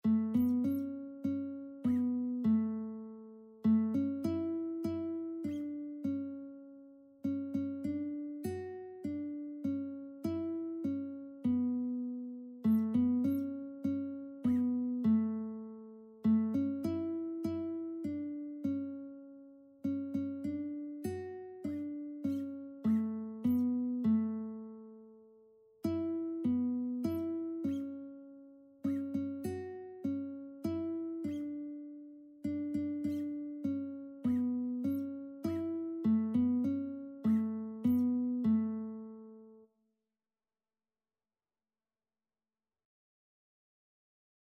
Christian Christian Lead Sheets Sheet Music Trust and Obey
A major (Sounding Pitch) (View more A major Music for Lead Sheets )
3/4 (View more 3/4 Music)
Classical (View more Classical Lead Sheets Music)